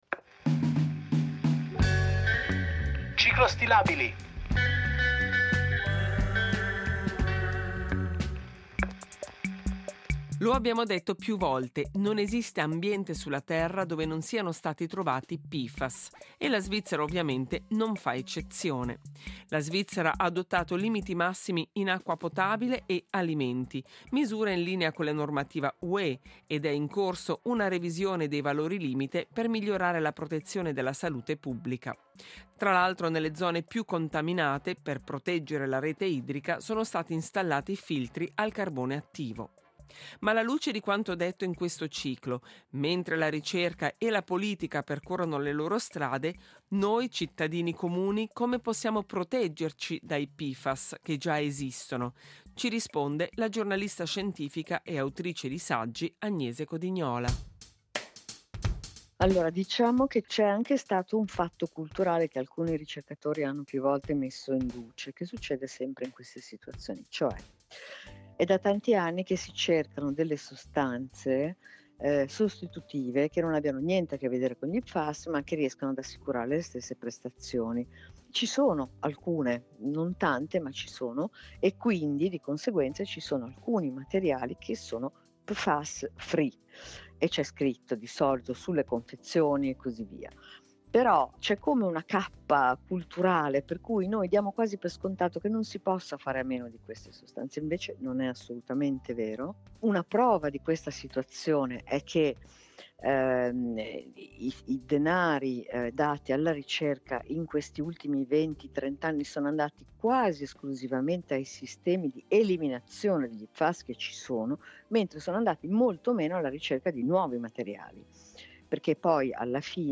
ha intervistato la giornalista scientifica